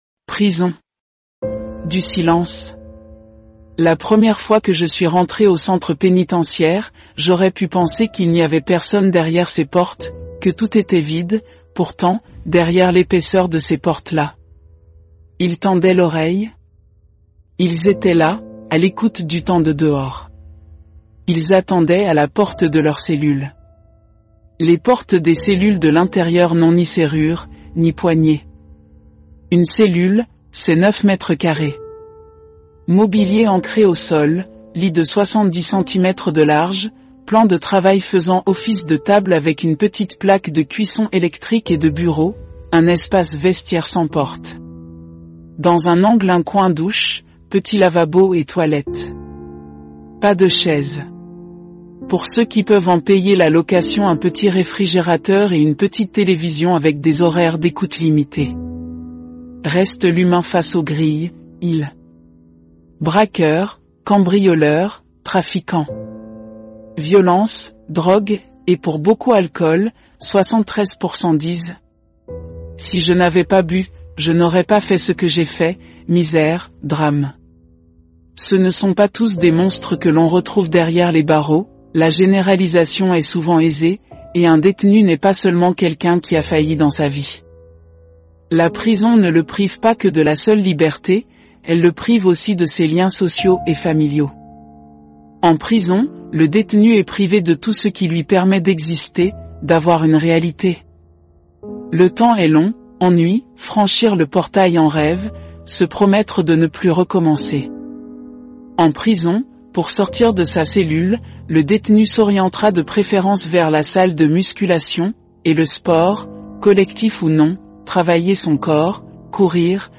Le son du texte ci-dessous a été généré par un outil de synthèse vocale (TTS) pour un rendu audio « réaliste »